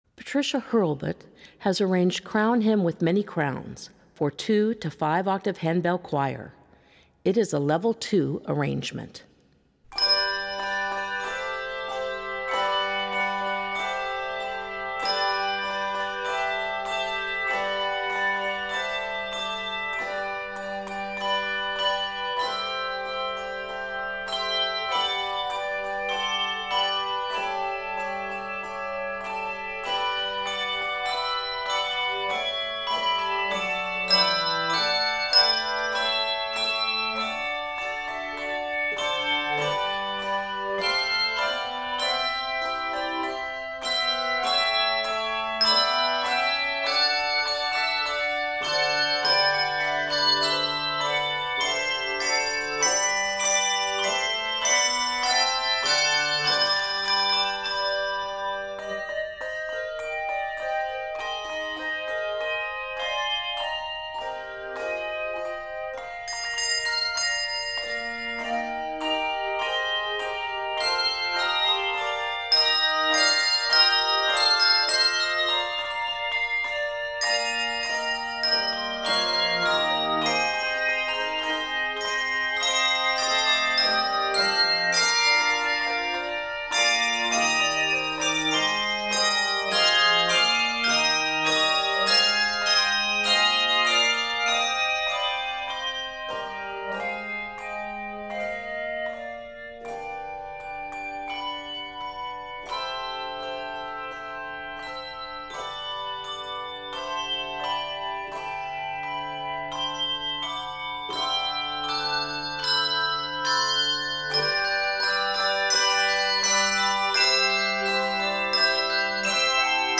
Keys of G Major and Eb Major.